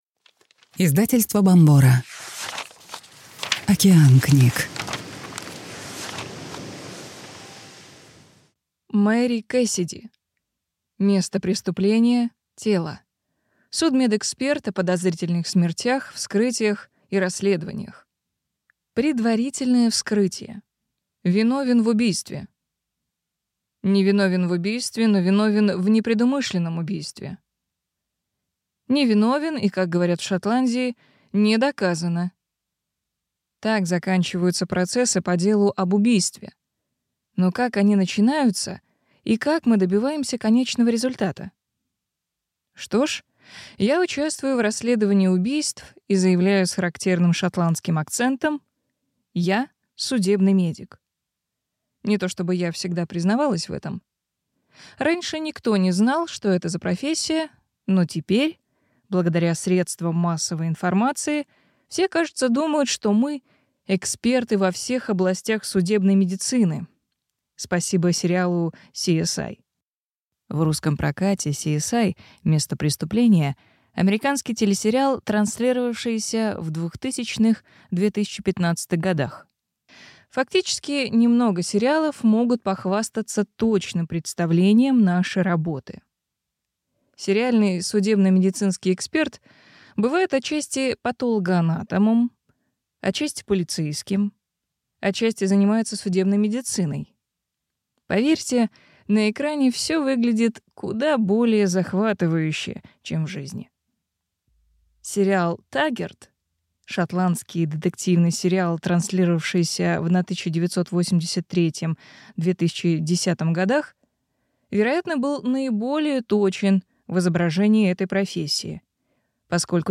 Аудиокнига Место преступления – тело. Судмедэксперт о подозрительных смертях, вскрытиях и расследованиях | Библиотека аудиокниг